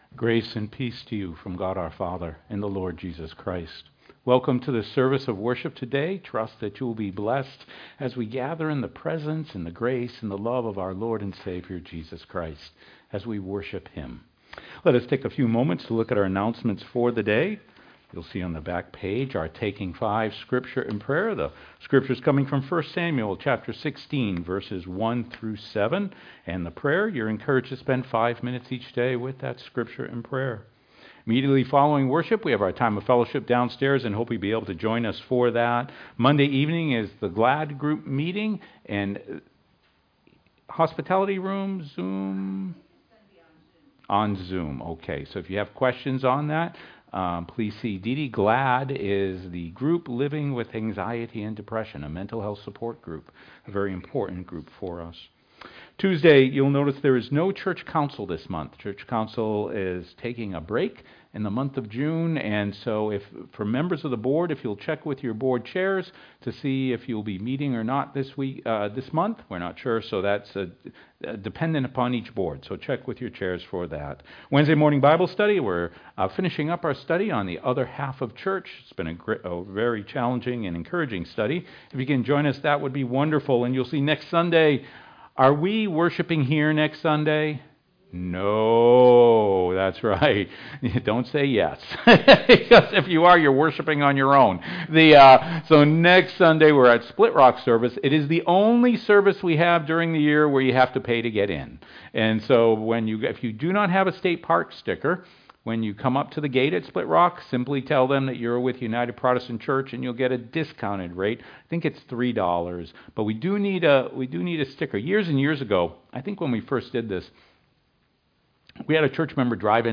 sermon-1.mp3